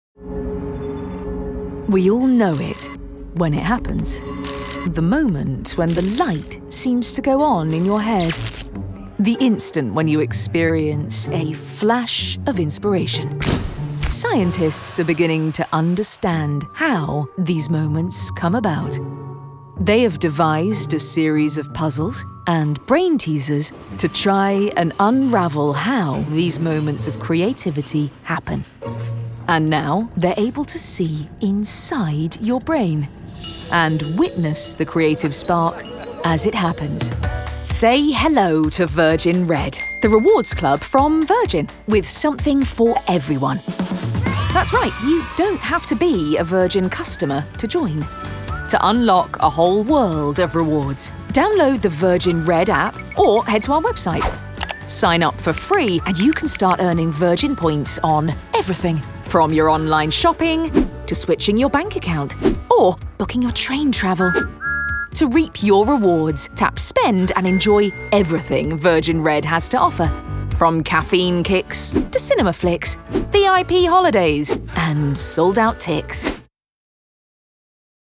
Female
English (British)
My natural vocal tone is sincere, friendly and direct with a clarity and warmth.
Elearning Documentary
Words that describe my voice are Warm, Confident, Friendly.